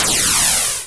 slime_death.wav